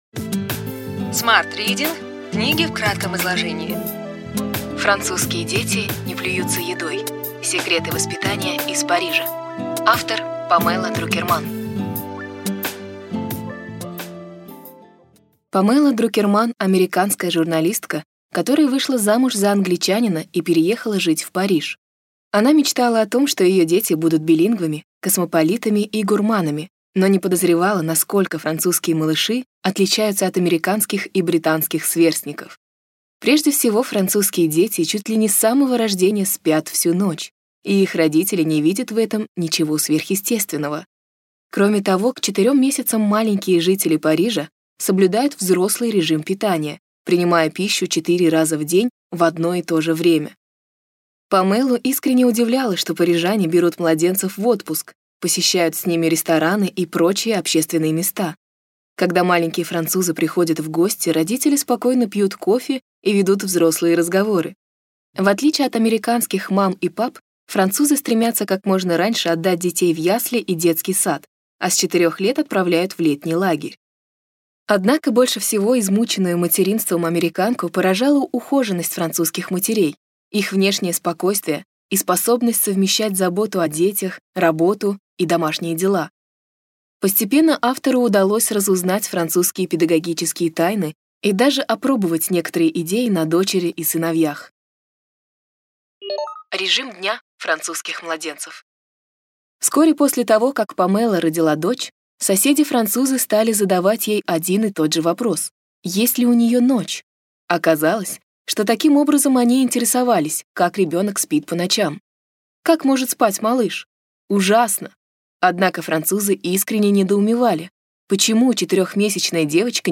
Аудиокнига Ключевые идеи книги: Французские дети не плюются едой. Секреты воспитания из Парижа. Памела Друкерман | Библиотека аудиокниг